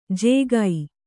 ♪ yēgai